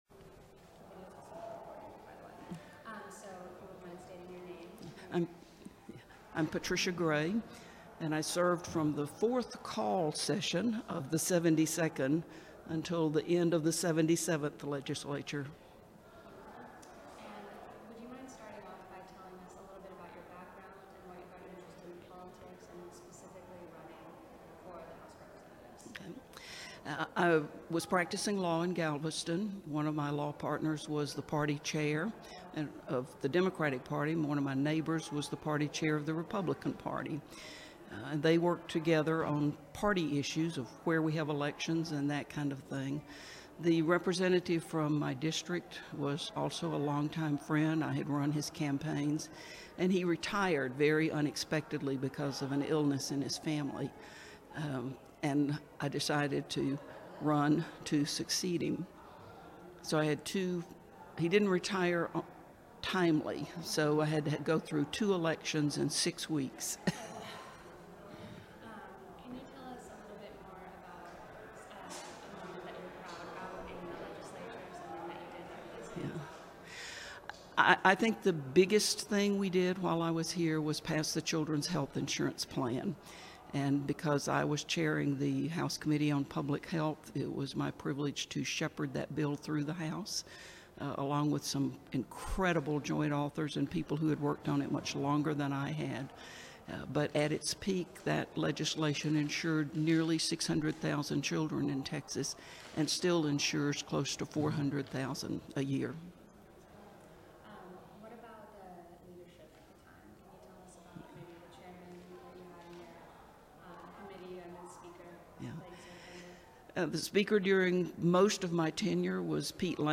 • Oral history interview with Patricia Gray, 2015. Texas House of Representatives.